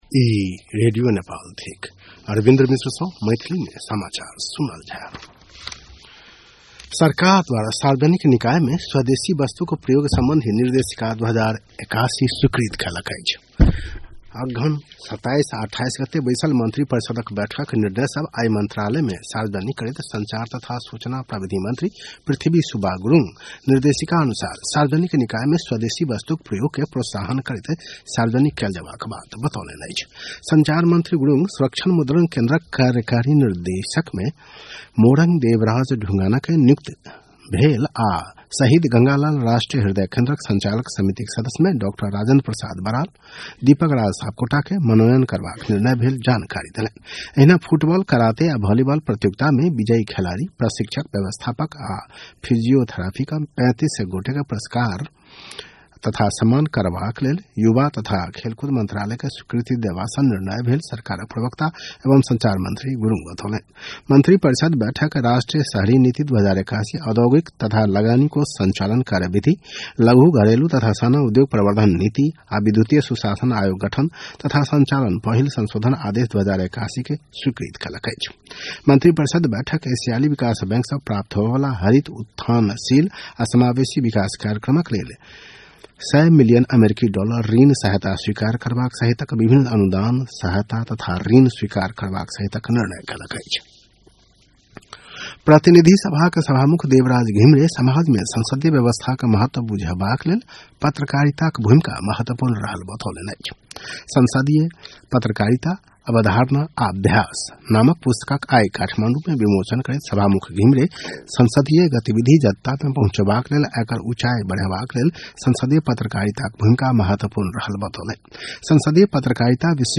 मैथिली भाषामा समाचार : २ पुष , २०८१
6-PM-Maithali-News-9-1.mp3